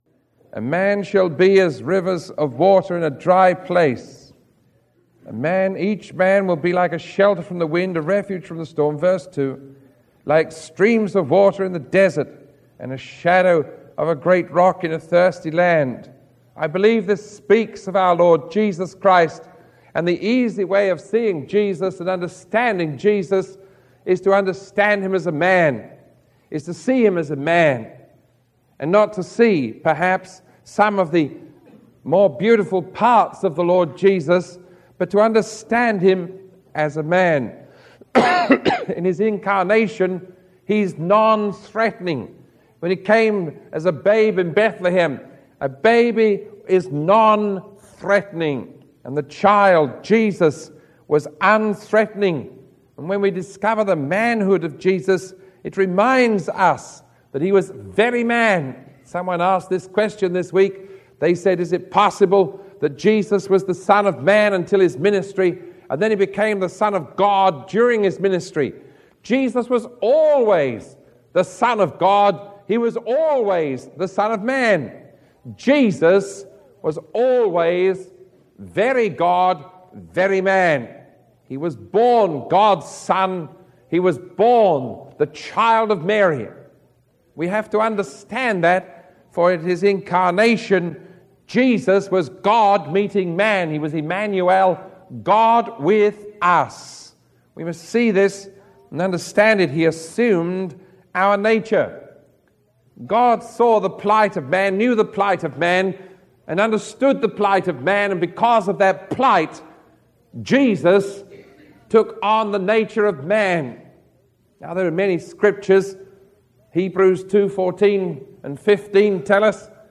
Sermon 0967A recorded on January 20